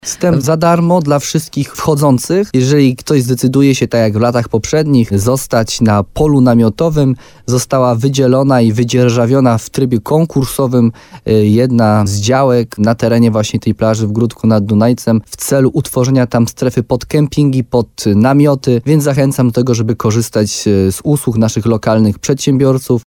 – W tym roku będzie tu po raz pierwszy gminna plaża – mówił w programie Słowo za Słowo na antenie RDN Nowy Sącz wójt gminy Gródek nad Dunajcem Jarosław Baziak.